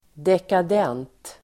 Ladda ner uttalet
dekadent adjektiv, decadent , blasé Uttal: [dekad'en:t] Böjningar: dekadent, dekadenta Synonymer: förfallen Definition: förfinad och blasé; urartad (sophisticated and world-weary; decadent, marked by decay or decline)